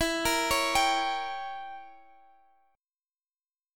Gdim/E Chord